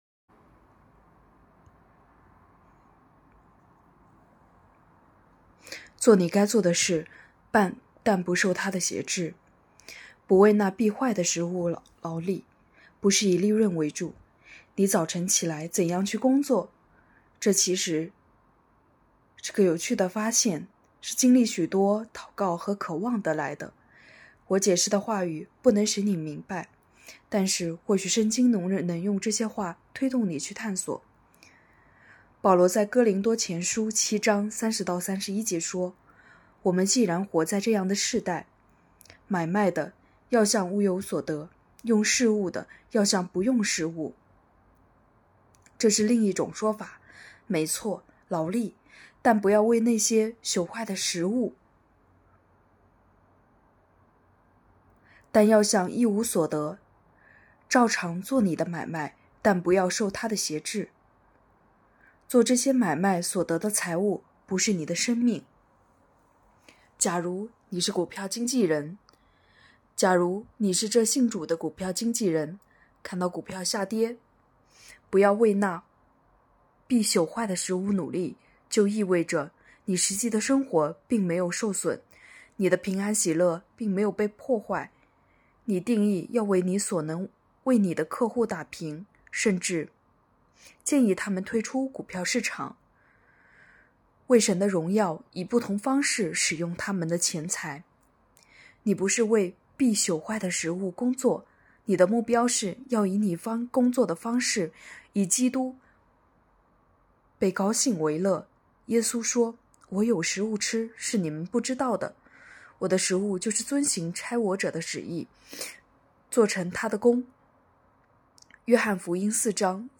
2024年8月2日 “伴你读书”，正在为您朗读：《活出热情》 欢迎点击下方音频聆听朗读内容 音频 https